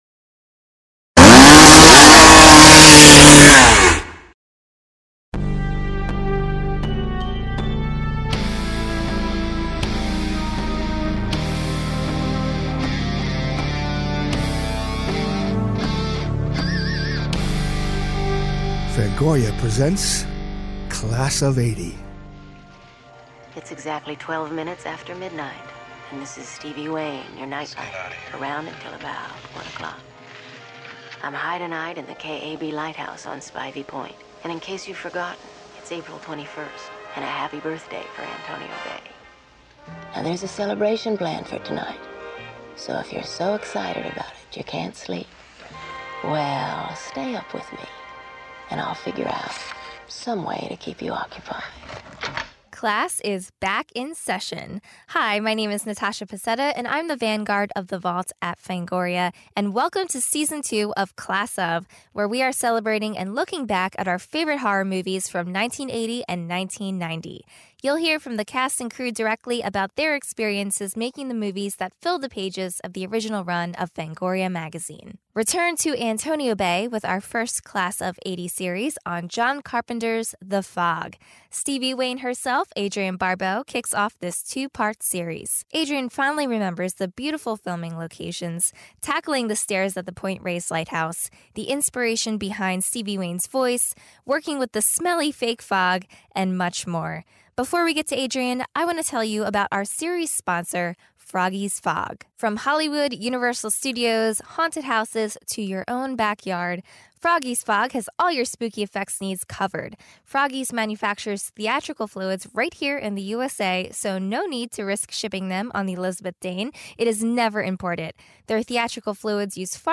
Stevie Wayne herself, Adrienne Barbeau , kicks off this two-part series.